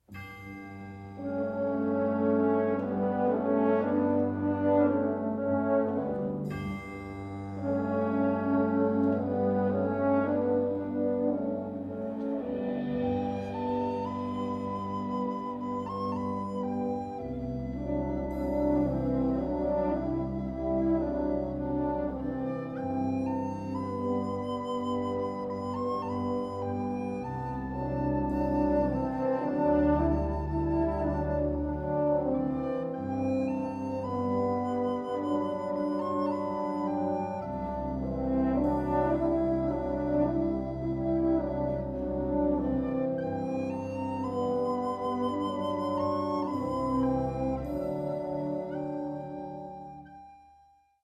Catégorie Harmonie/Fanfare/Brass-band
Sous-catégorie Musique de concert
Part 4 - Tenor Saxophone